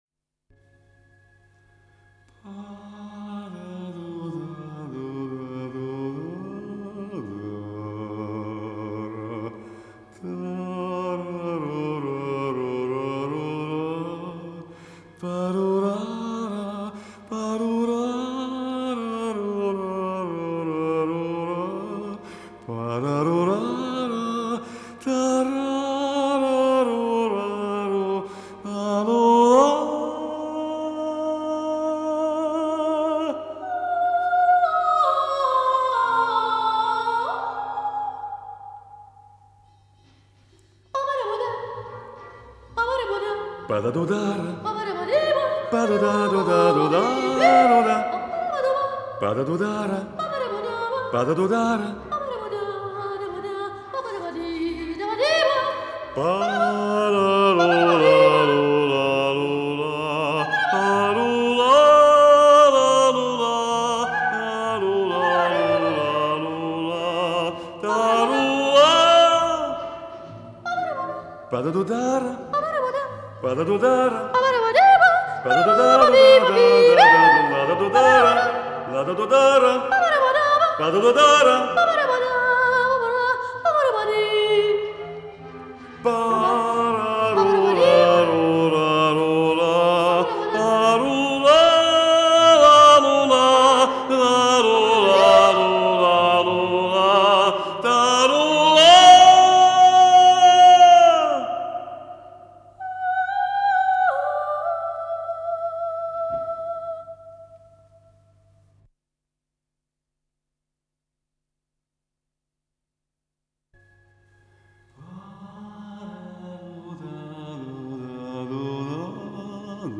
с профессиональной магнитной ленты
Вокализ (исп. вок. дуэт) мужск. и женские голоса
2. Вокализ (исп. вок. дуэт) мужск. и женские голоса
3. Вокализ (исп. солистка без сопровожд.)
4. Вокализ (исп. вок. дуэт) муж. и женск. голоса
Скорость ленты38 см/с